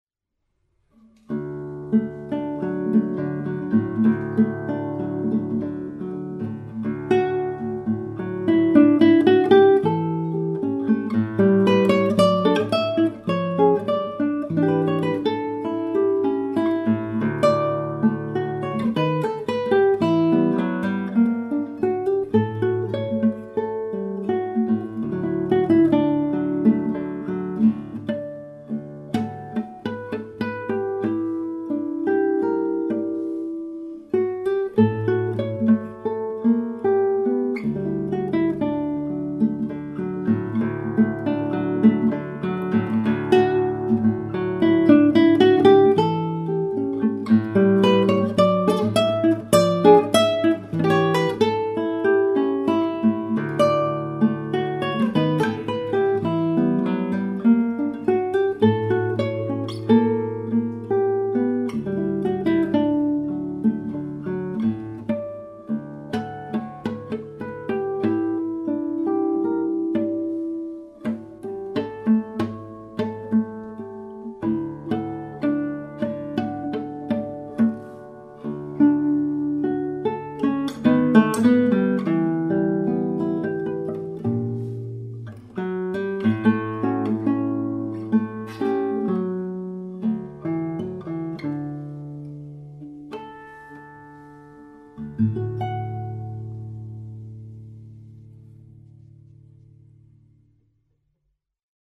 Cançon de lladre, chanson Catalane transcrite par Llobet
Morceau magnifique à    la musicalité délicieuse et au parfum bien Catalan!!!
Côté interprétation, il y a une grande force... Peut-être même trop de force...